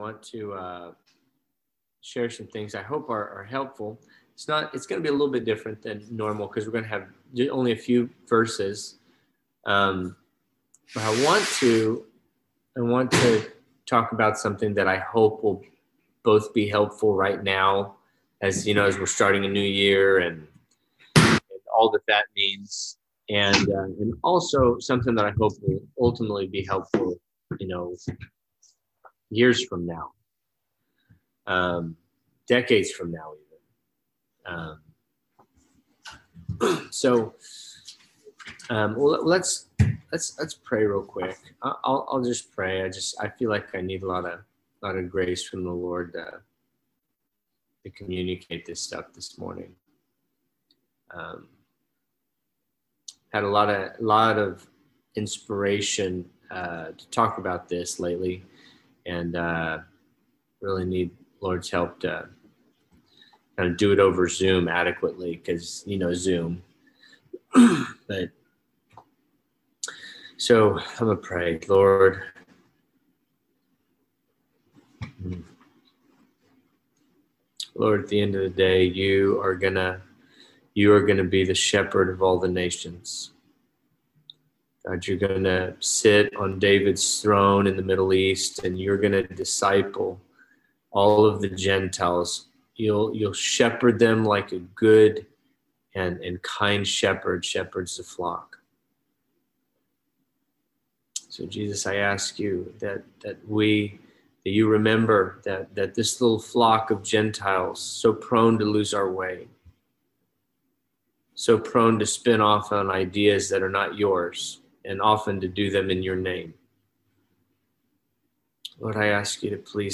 This is a 2-part teaching done in a small group setting a few years back. It was an encouragement at the start of the new year to begin using your schedule and routine as tools to direct the heart towards God and the things that He cares about.